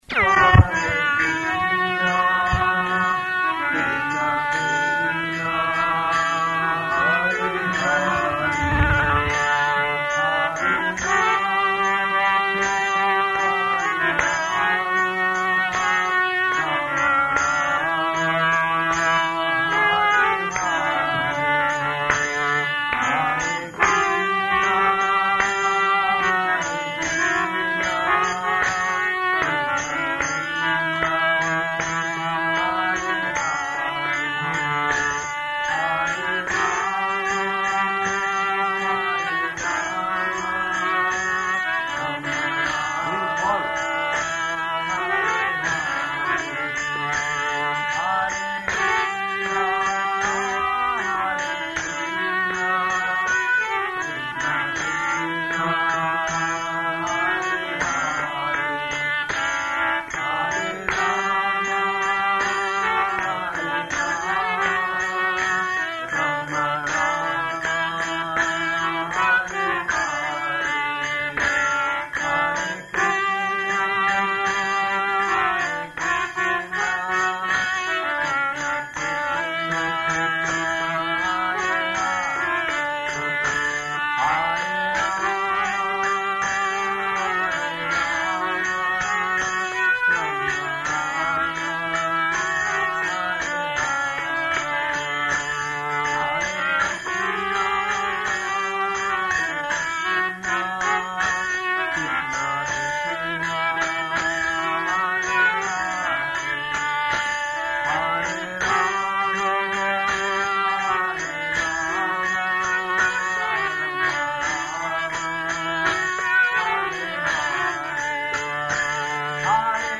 Bhajana with Harmonium, Room Conversation
Bhajana with Harmonium, Room Conversation --:-- --:-- Type: Conversation Dated: November 10th 1973 Location: Delhi Audio file: 731110R1.DEL.mp3 [ Bhajana with harmonium] Prabhupāda: You follow.